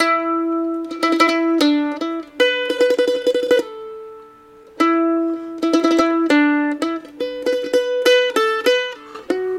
曼多1号音符CD 100 Bpm